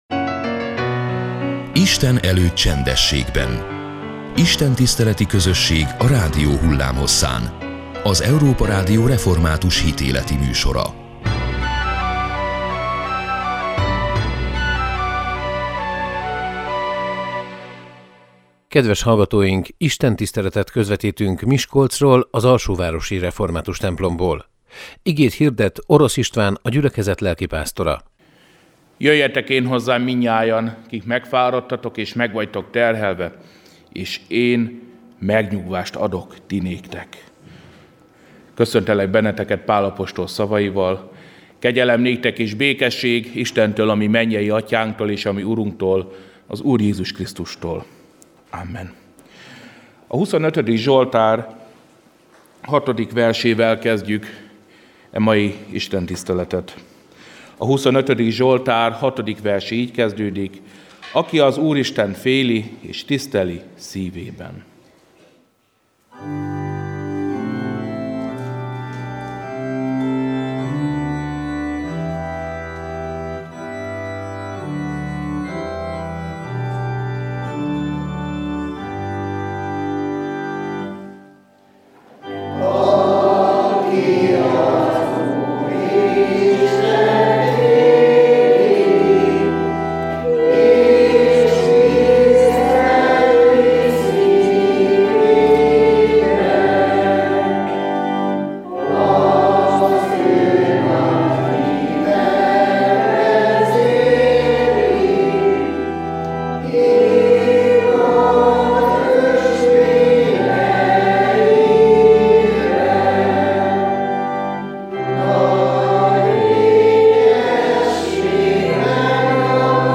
Istentisztelet